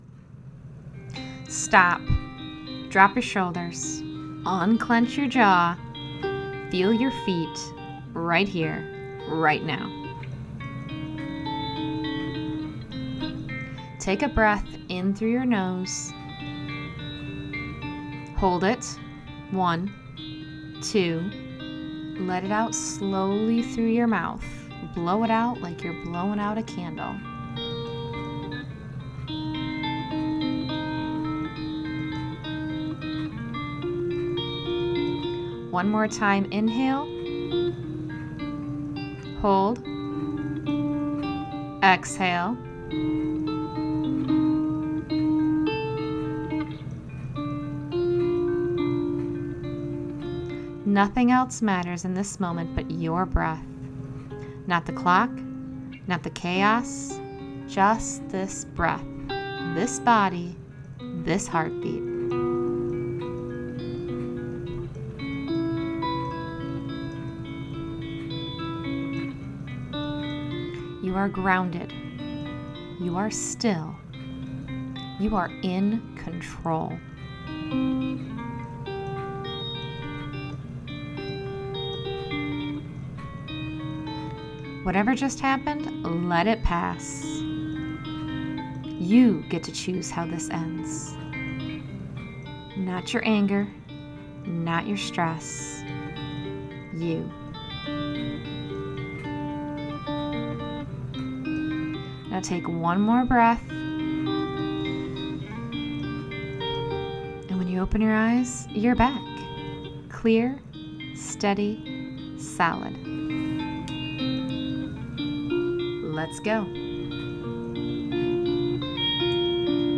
Guided Audio Emotional Resets